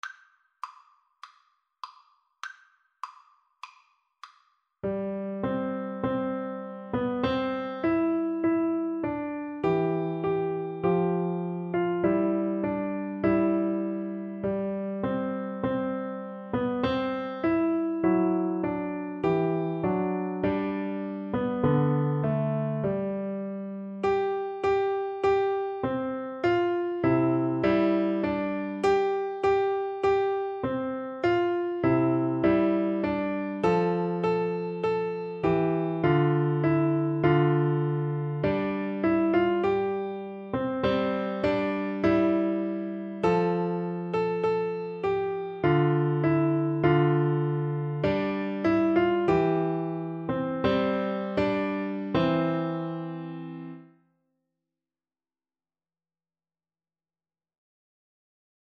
4/4 (View more 4/4 Music)
Piano Duet  (View more Beginners Piano Duet Music)
Classical (View more Classical Piano Duet Music)